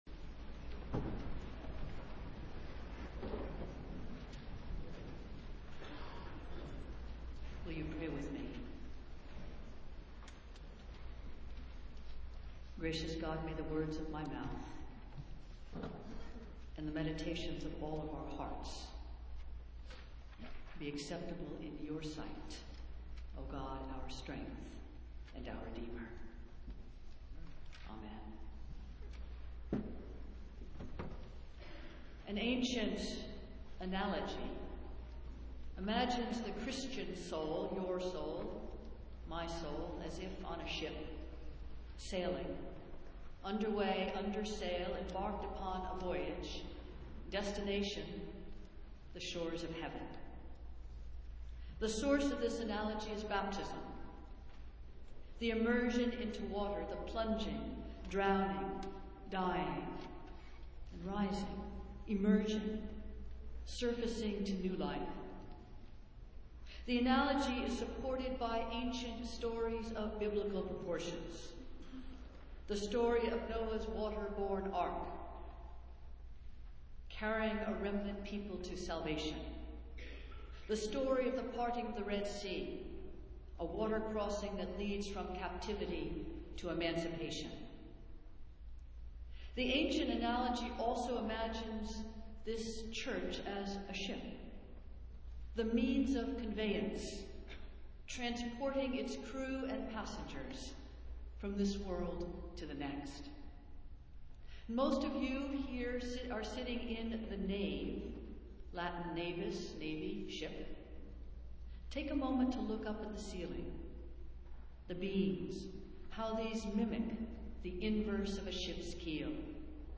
Festival Worship - Fifth Sunday in Lent